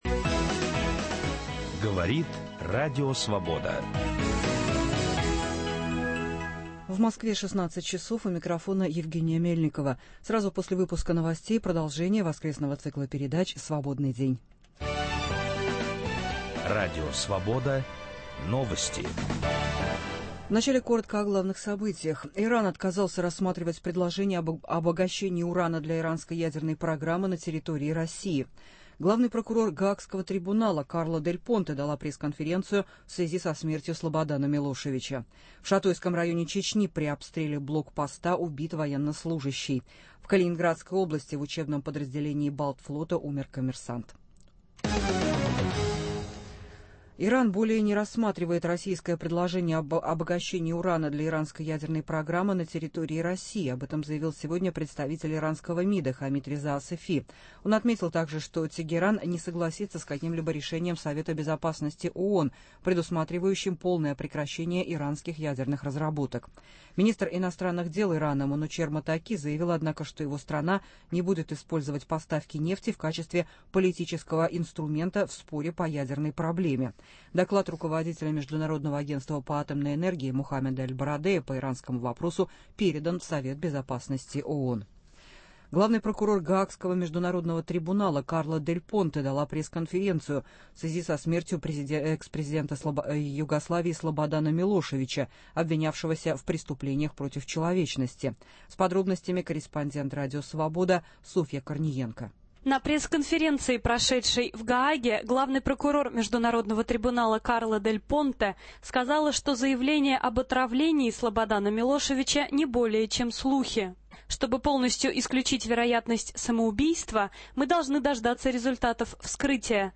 Из-за чего распадаются смешанные браки? В программе размышляют: психолог, писательница, историк, заграничный жених и прохожие на улице.